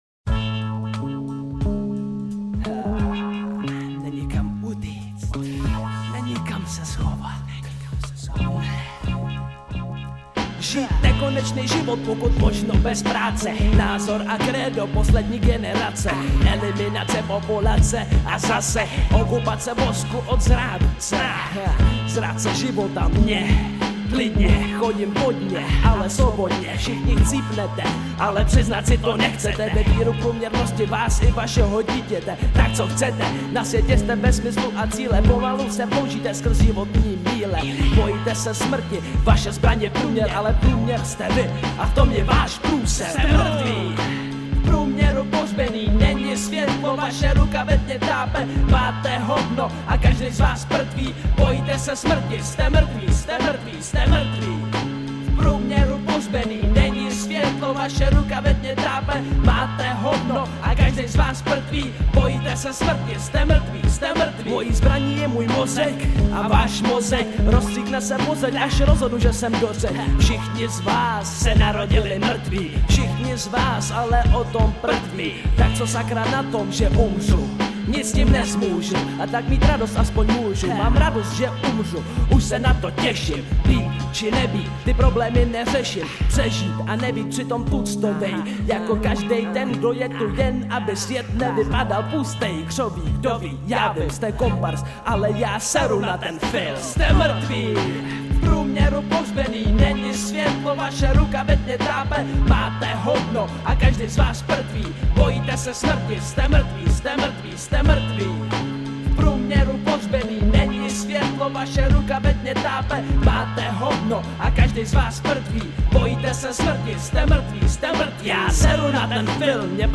16 Styl: Hip-Hop Rok